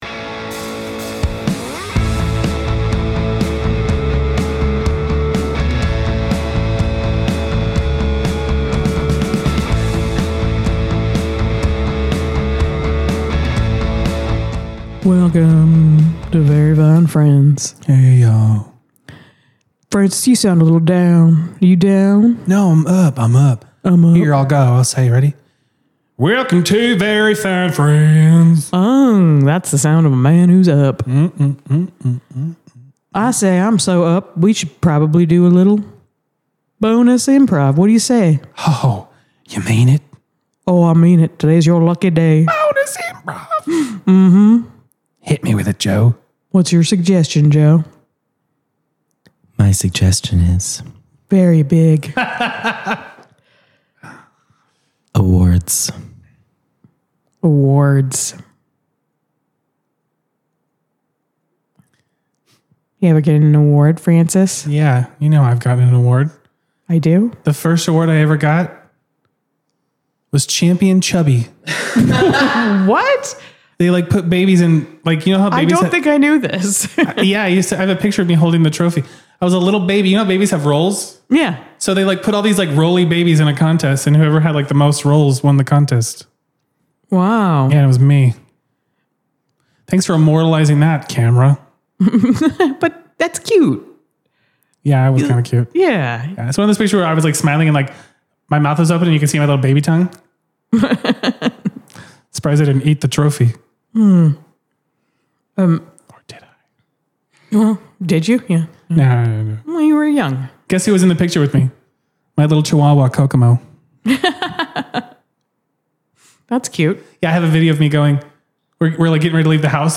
Bonus IMPROV - Yeah, It Implies Cost